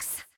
HeavenStudio/Assets/Bundled/Games/CheerReaders/Sounds/Girls/LetsGoRead/bunchaBooksgirls9.ogg at d214adae01d4ab0f8e4bc733225ee45d0eb83c7e
bunchaBooksgirls9.ogg